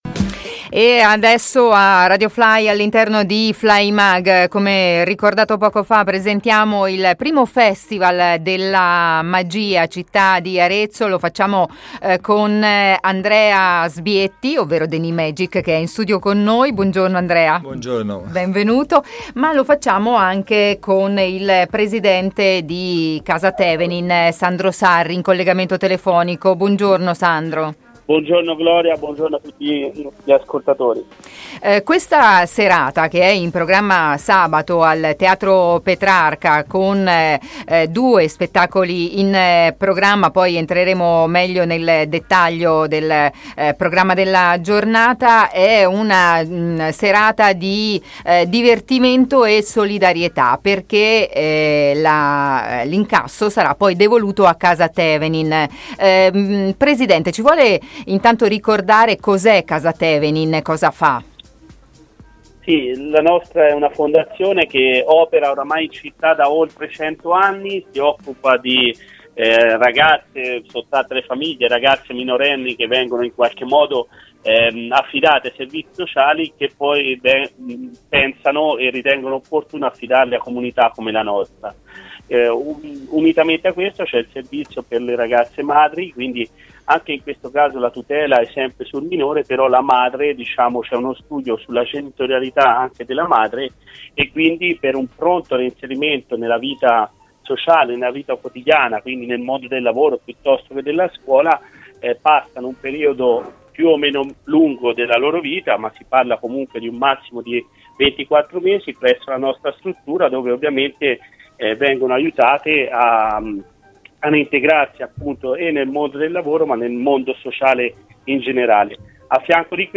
La presentazione del 1° Festival della Magia Città di Arezzo, nell’intervista
in collegamento telefonico